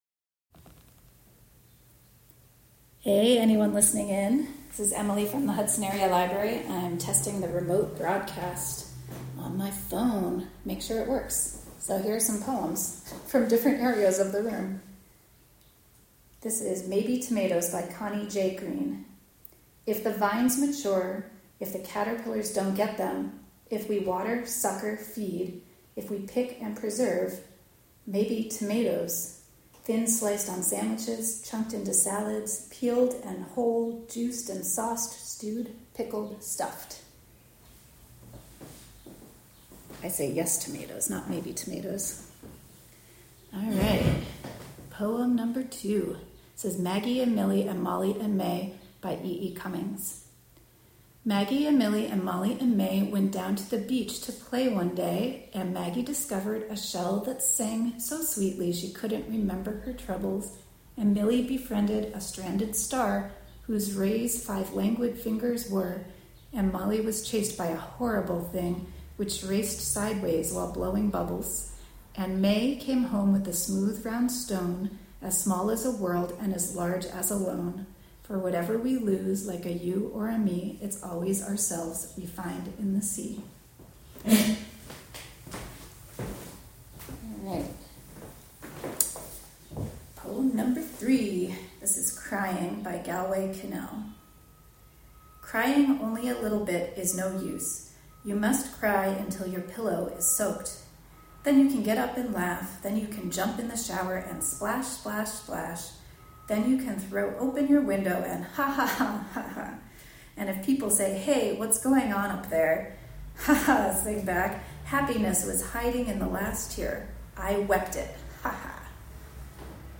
Live from the Hudson Area Library
ARCHIVE Live from the Hudson Area Library: Test broadcast poetry (Audio) Apr 29, 2024 shows Live from the Hudson Area Library Testing the remote broadcast option. Enjoy some poems!